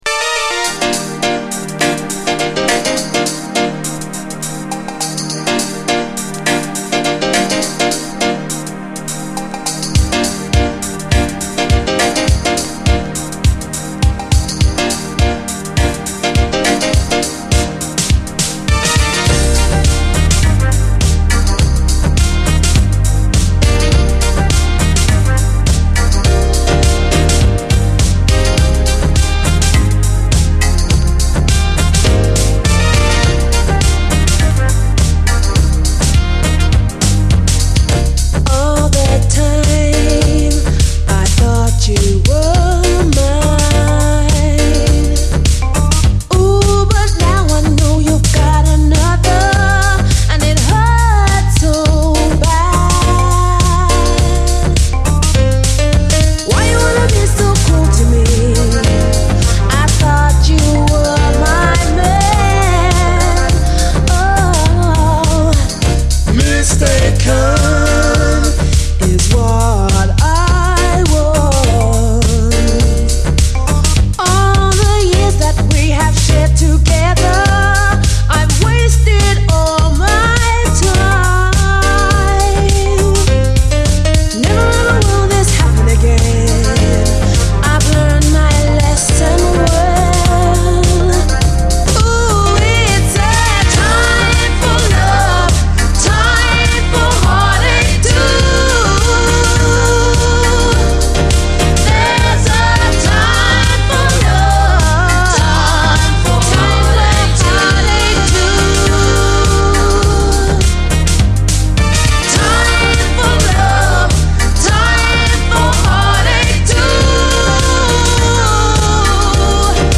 SOUL, 70's～ SOUL
最高レアUKストリート・ソウル！
ドスドスと真っ黒く打ってる通常ミックスもカッコいい。